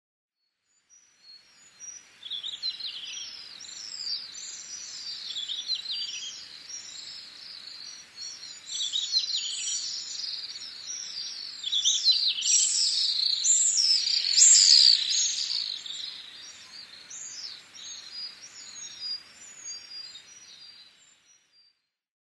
アマツバメ　Apus pacificusアマツバメ科
日光市稲荷川上流　alt=1420m
Mic: Panasonic WM-61A  Binaural Souce with Dummy Head
すぐ近くをアマツバメが横切ります。羽音が聞こえます。
他の自然音：ルリビタキ